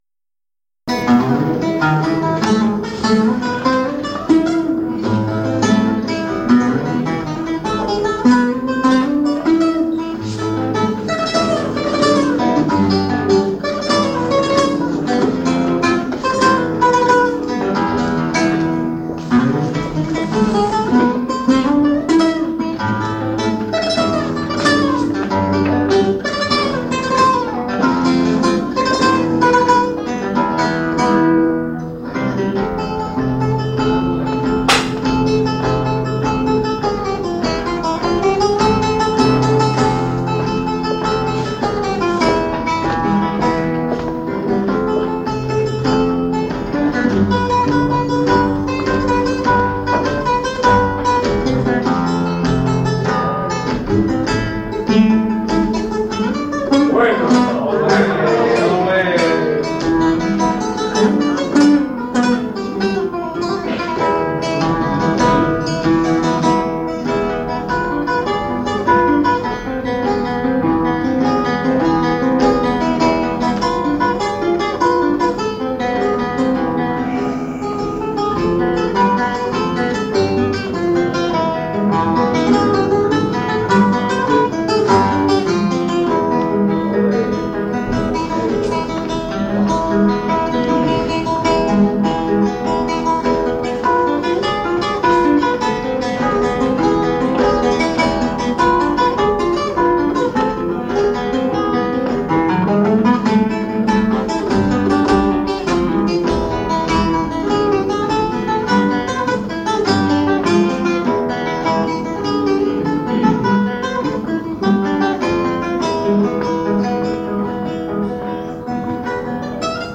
EN CONCIERTO